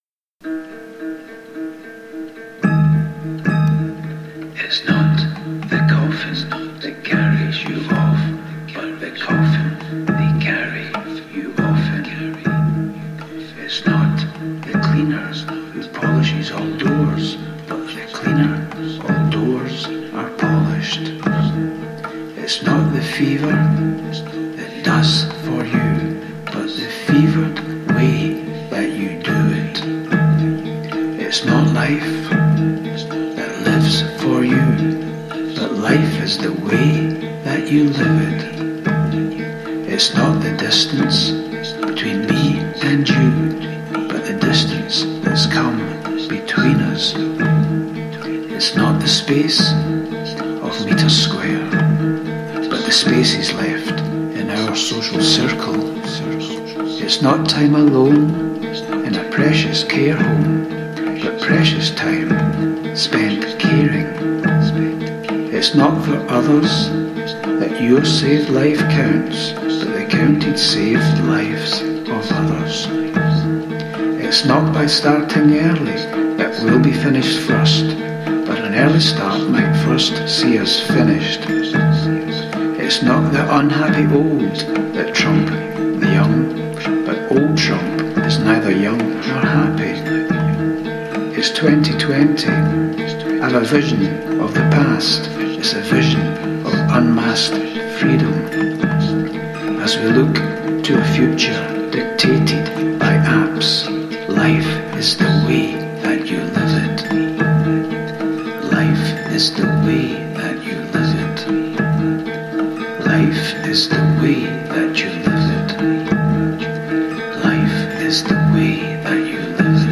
Follow the link to a dystopian poem for these increasingly dystopian times, with the words set to one unresolved chord